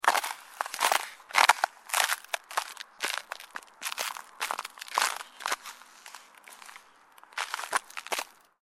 Ice-sound-effect.mp3